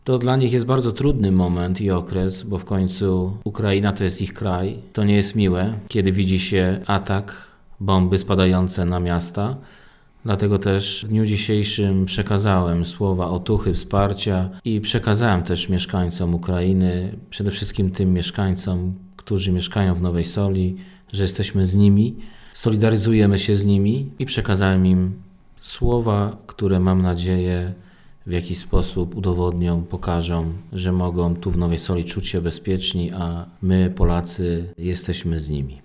Dzisiejsza sesja Rady Miasta Nowa Sól miała inne zakończenie niż zwykle. Związane było to z agresja Rosji na Ukrainę.
Przewodniczący Andrzej Petreczko przekazał słowa wsparcia mieszkańcom, narodowości ukraińskiej.